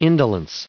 added pronounciation and merriam webster audio
885_indolence.ogg